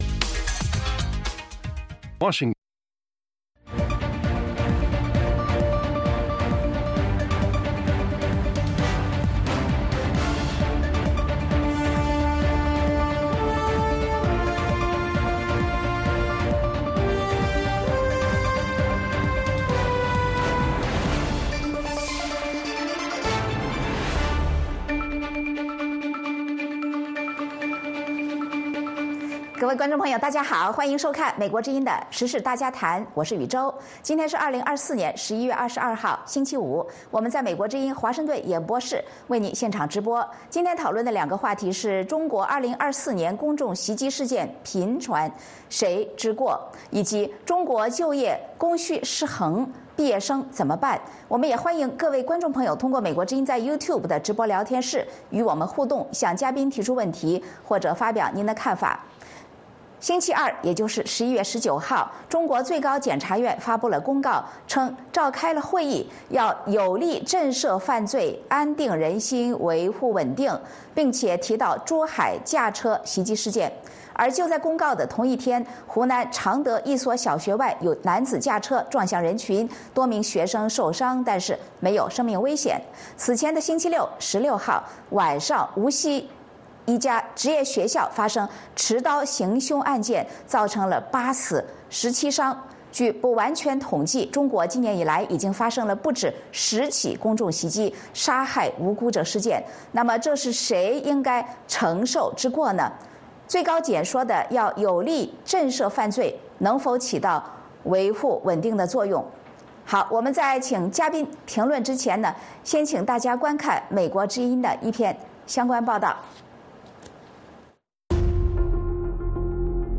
美国之音中文广播于北京时间晚上9点播出《时事大家谈》节目(电视、广播同步播出)。《时事大家谈》围绕重大事件、热点问题、区域冲突以及中国内政外交的重要方面，邀请专家和听众、观众进行现场对话和讨论，利用这个平台自由交换看法，探索事实。